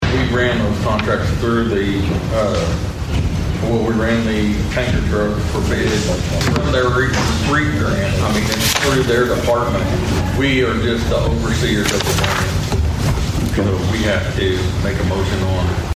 District two commissioner Steve Talburt explains that process.